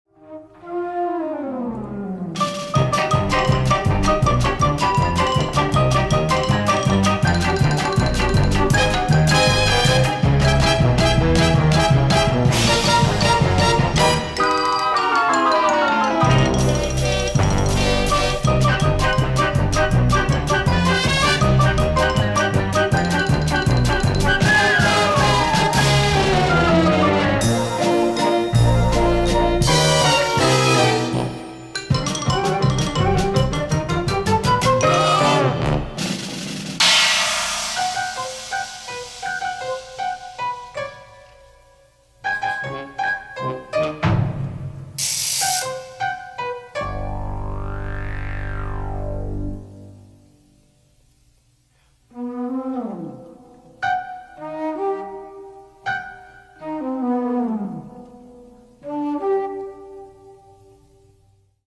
The score was created with a big band
guitar
bass
drums
synthesizer